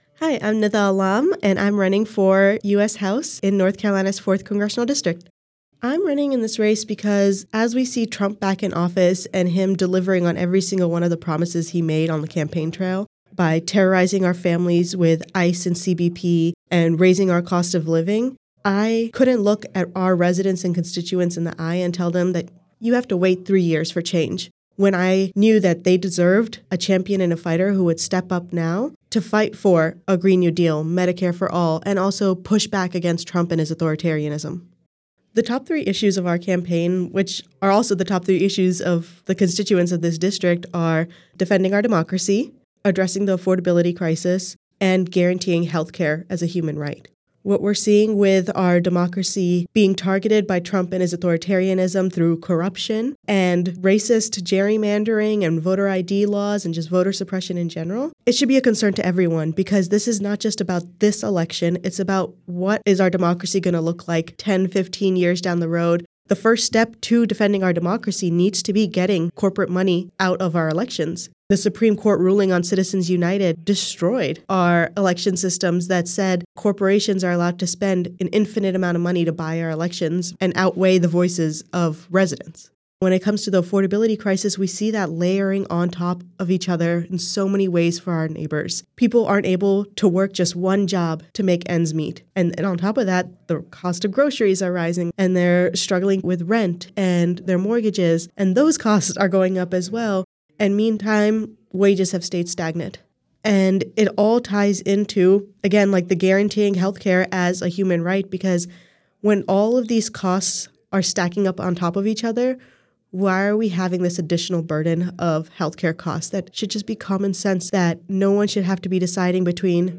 Their answers (which are edited for clarity and brevity) are shared here, as well as links to their respective campaign websites or pages.
97.9 The Hill spoke with each candidate, asking these questions that are reflected in the recorded responses: